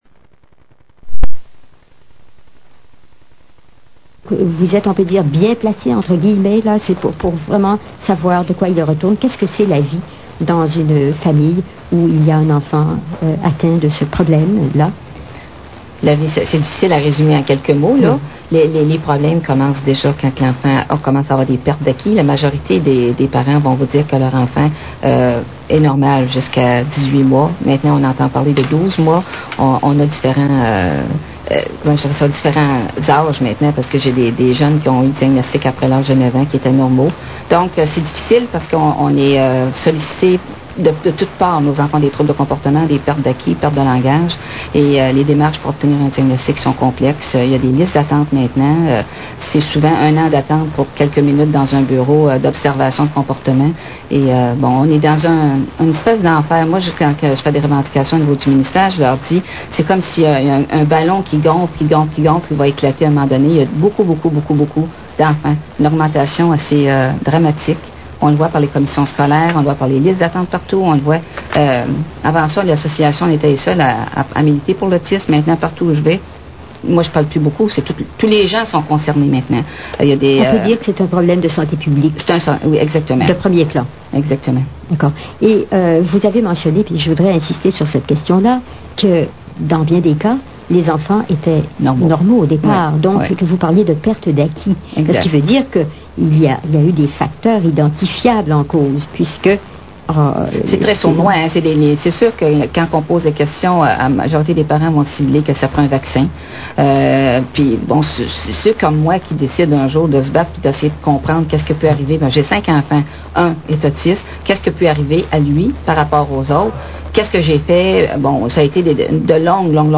Une �mission radiophonique sur l'autisme et les amalgames dentaires